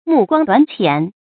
注音：ㄇㄨˋ ㄍㄨㄤ ㄉㄨㄢˇ ㄑㄧㄢˇ
目光短淺的讀法